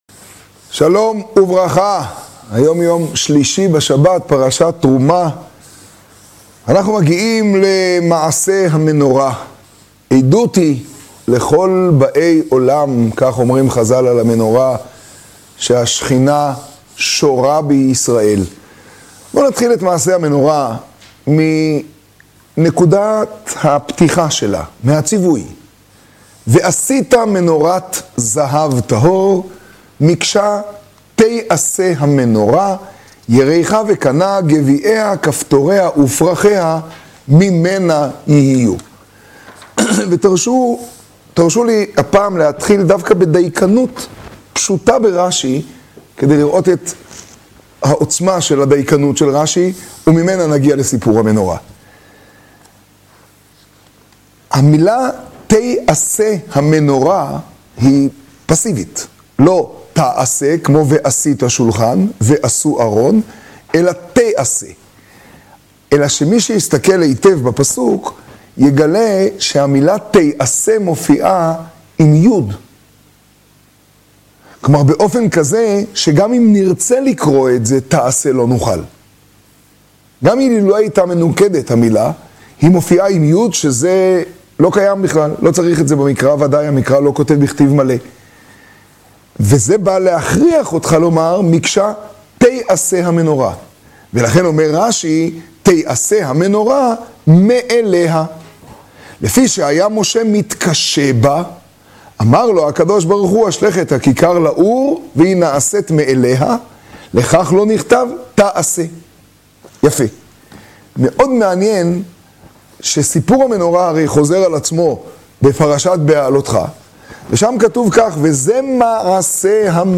לימוד פרשת תרומה תשעה.